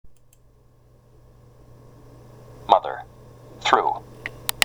ちなみに「スルー」のthはmotherのthとは違った発音です。